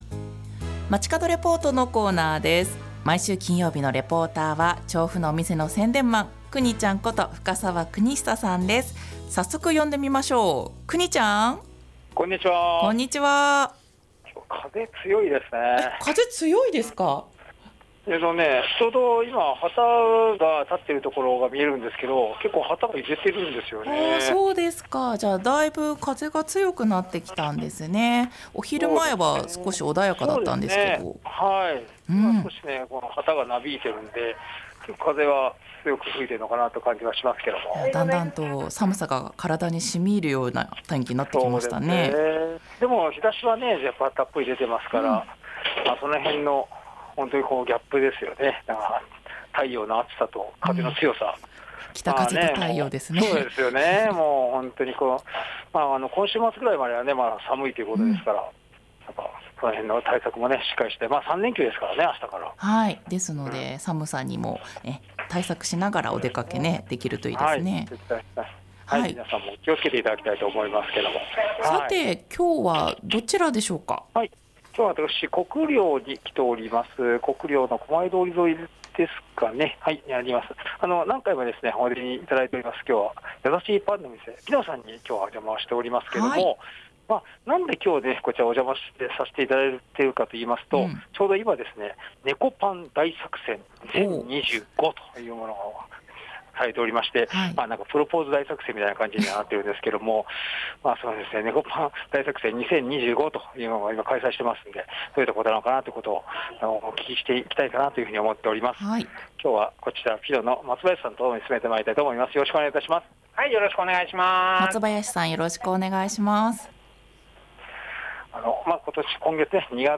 やさしいパンの店PINOさんにお邪魔しました。
本日は国領駅から狛江通り沿いのやさしいパンの店PINOさんからお届けしました。 今月限定で売り上げの一部が寄付にまわります、もっちりねこ食パンやねこクッキー 季節限定パンなどお楽しみください ねこ食パンアレンジコンテストも開催中です。